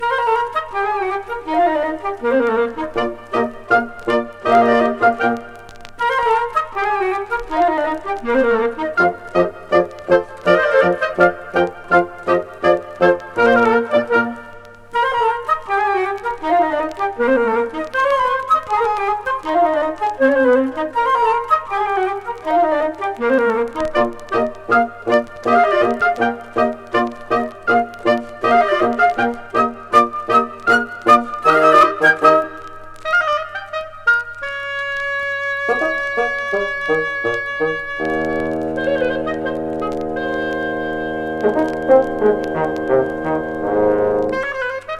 Classical, Stage & Screen　France　12inchレコード　33rpm　Stereo